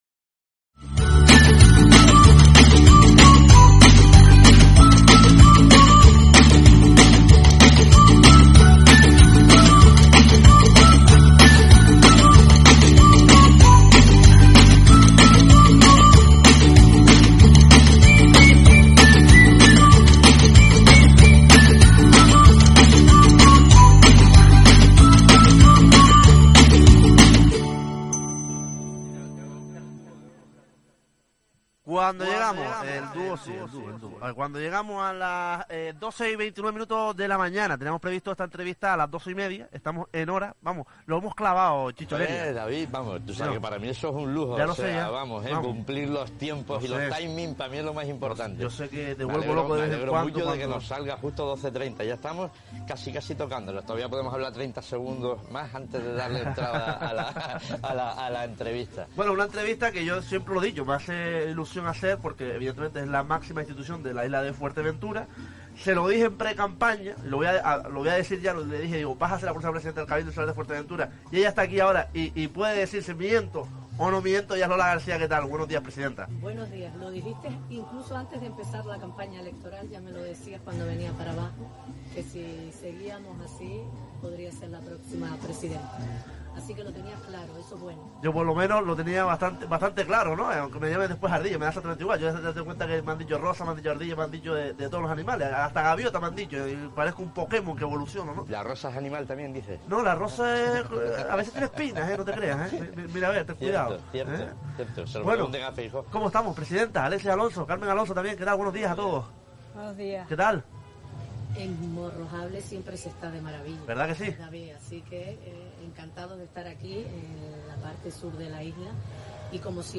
Hablamos en el día internacional del turismo con la Presidenta del Cabildo Insular de Fuerteventura, Lola García, el teniente de Alcalde de Pájara…